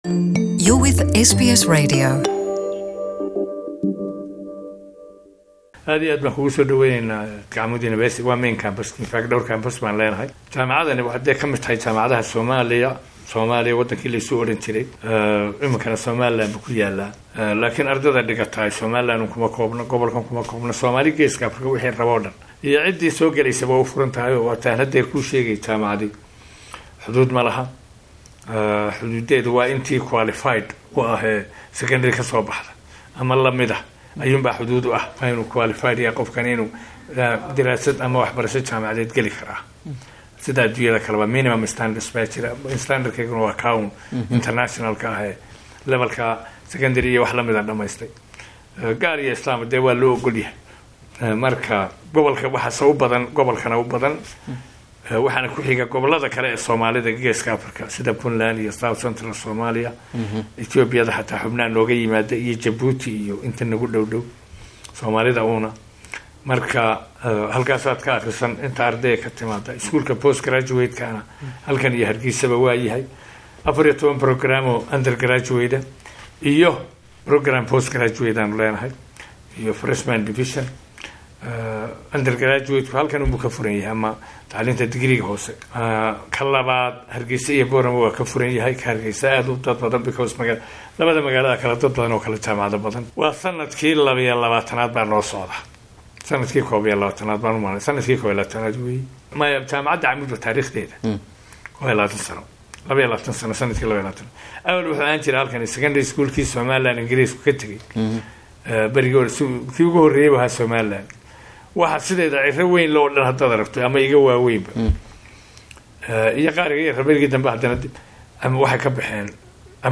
Amoud University Interview
Waraysi: Jaamacada Camuud